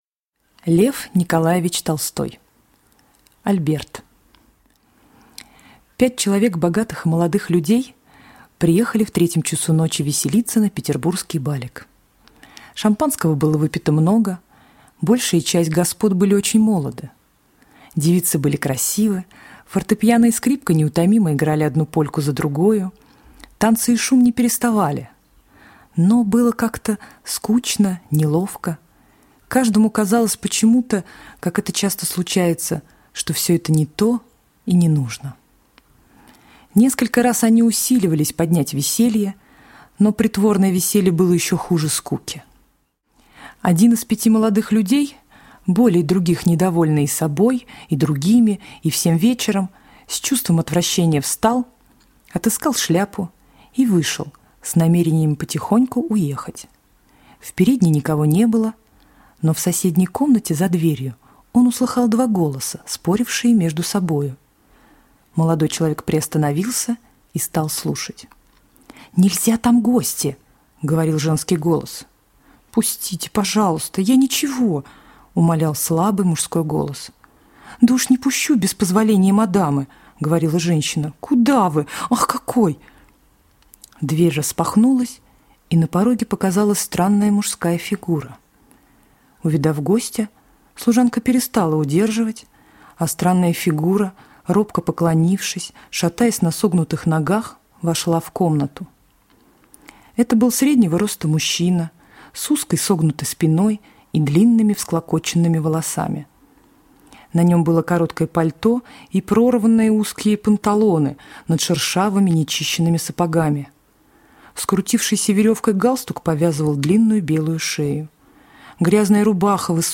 Aудиокнига Альберт